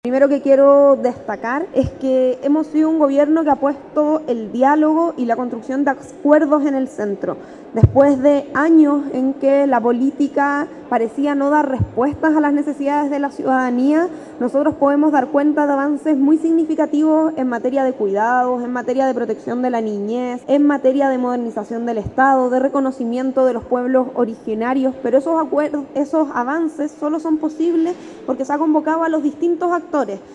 En dependencias del Teatro Municipal de Temuco se desarrolló la última cuenta pública del Ministerio de Desarrollo Social y Familia en el actual Gobierno, donde la ministra Javiera Toro, dio a conocer su balance respecto a los avances que se han realizado en la cartera durante el último año.